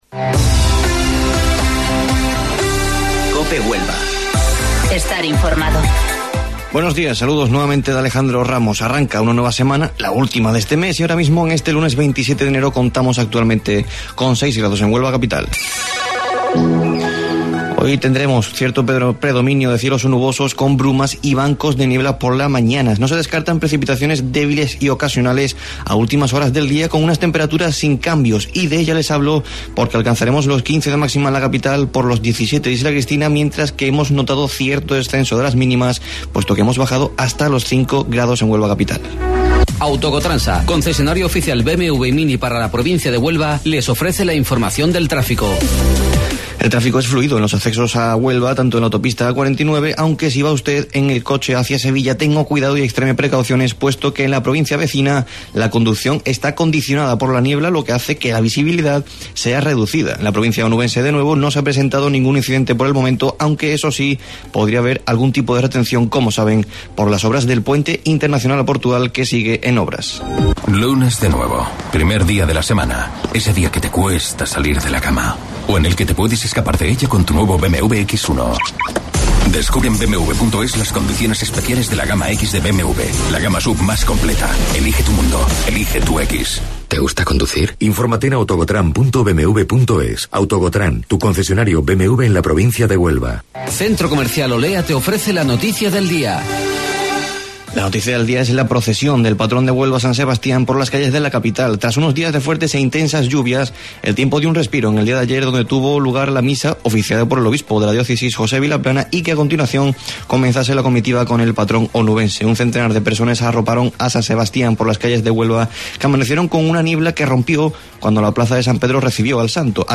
AUDIO: Informativo Local 08:25 del 27 Enero